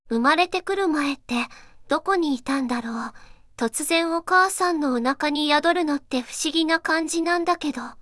例 ずんだもん 利用規約